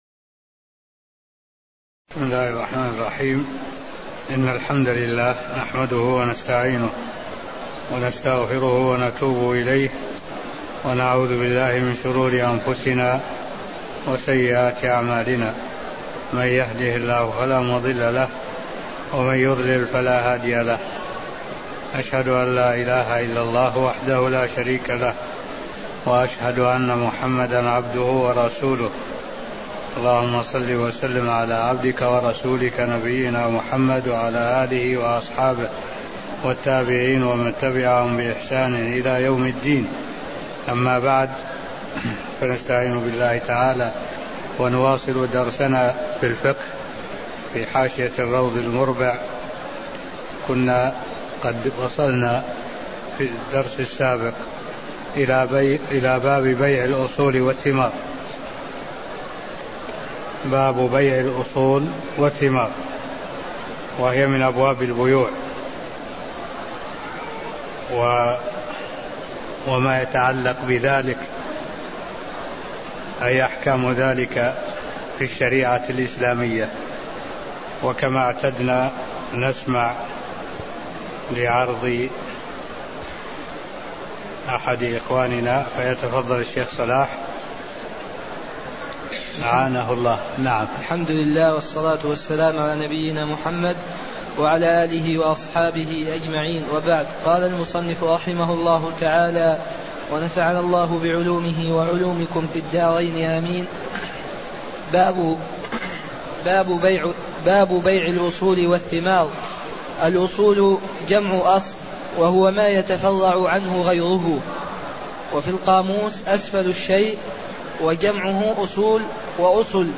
المكان: المسجد النبوي الشيخ: معالي الشيخ الدكتور صالح بن عبد الله العبود معالي الشيخ الدكتور صالح بن عبد الله العبود مقدمة باب مع الأصول والثمار (01)